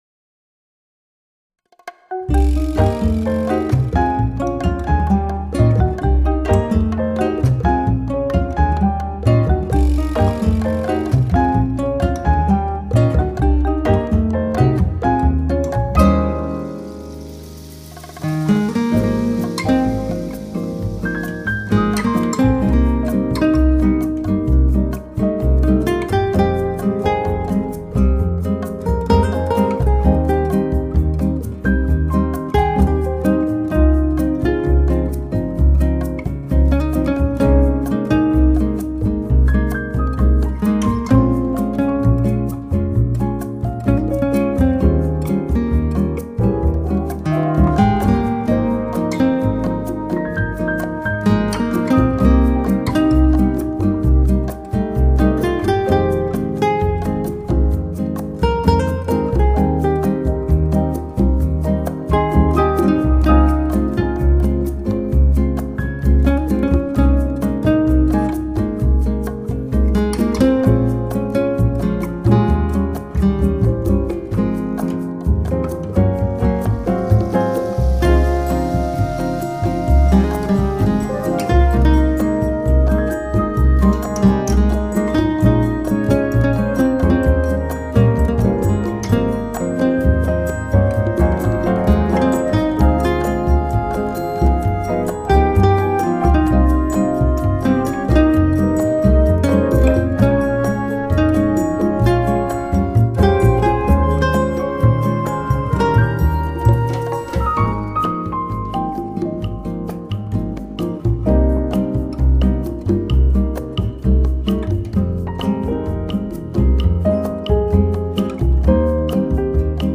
Genre: New Age, Neo-Classical, Piano